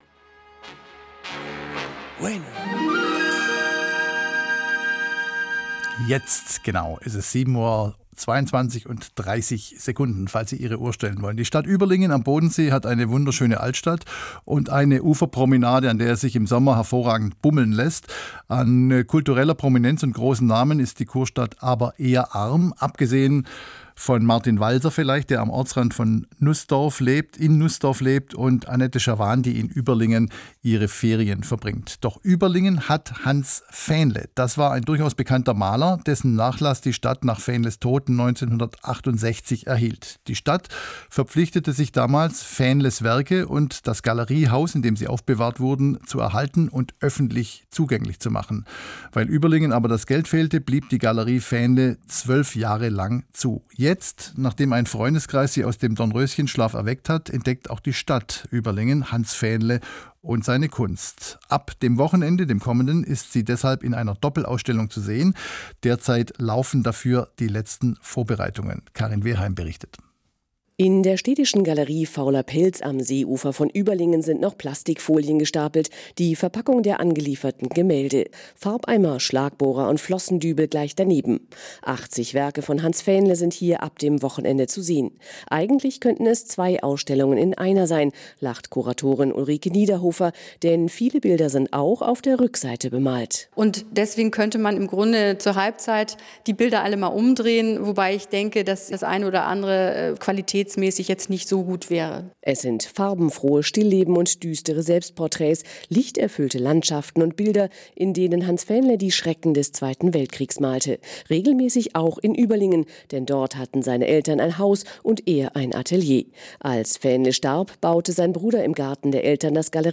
Galerie Fähnle im Radio - zum Nachhören